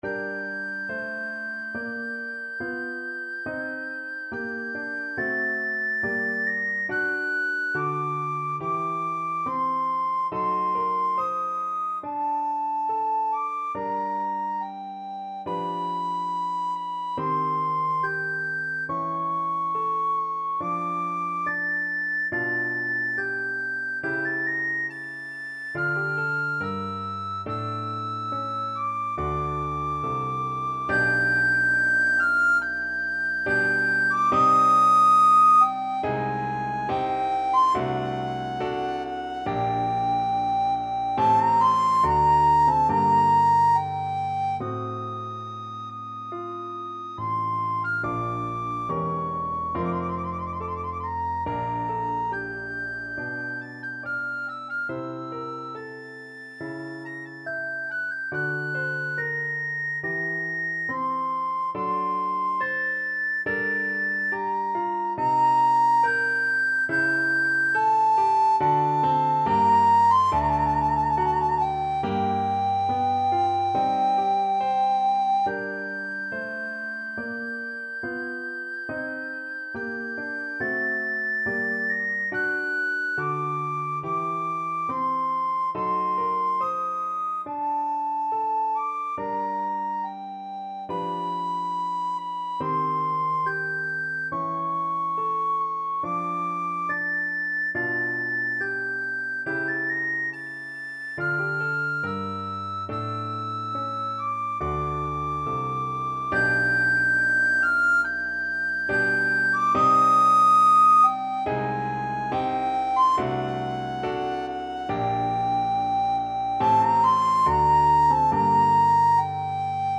Free Sheet music for Soprano (Descant) Recorder
4/4 (View more 4/4 Music)
Adagio =70
Classical (View more Classical Recorder Music)